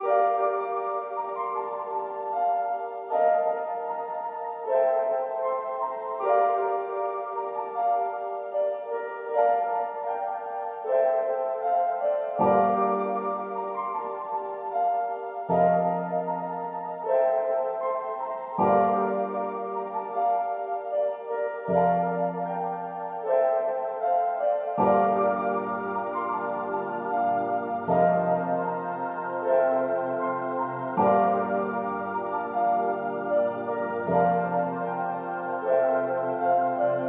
描述：奇怪的、实验性的钥匙、弹拨和垫子的组合 发布任何使用这个的节拍链接
标签： 155 bpm Trap Loops Pad Loops 6.24 MB wav Key : Unknown
声道立体声